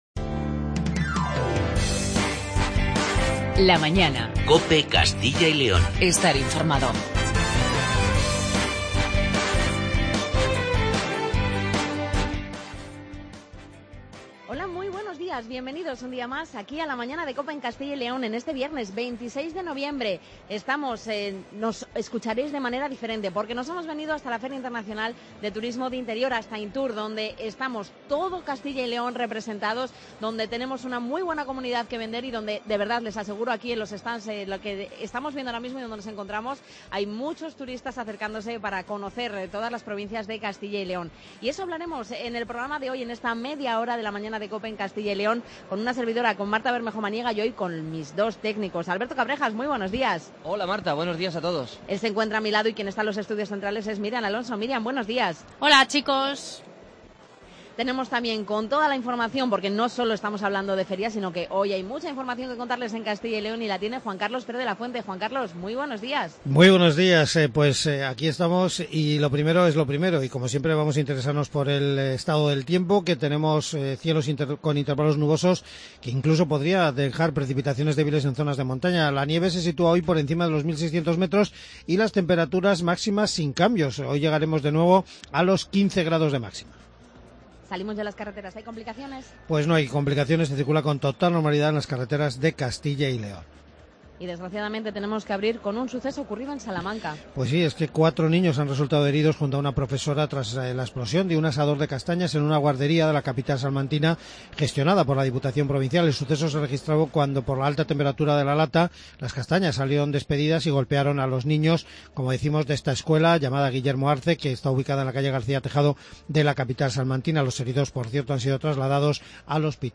Hoy, programa especial desde INTUR, Feria Internacional de Turismo de Interior